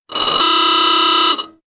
AA_sound_aoogah.ogg